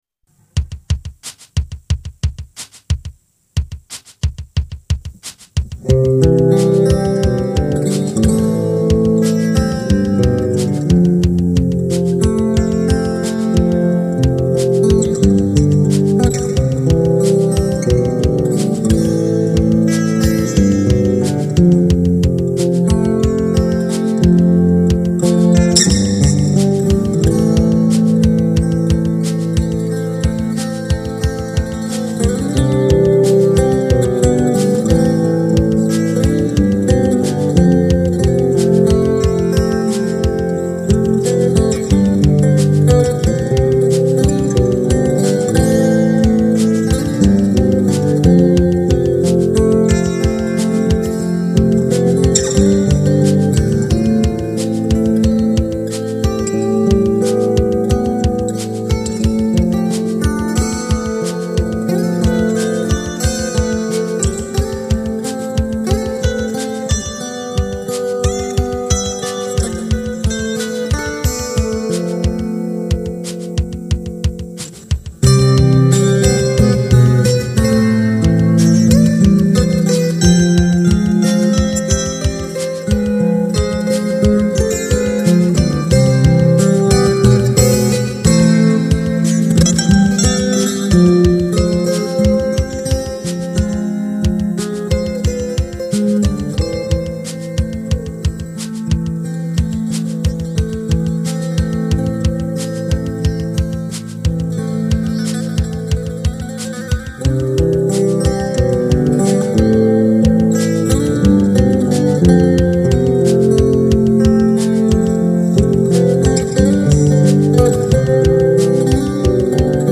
MP3音源はリマスターしました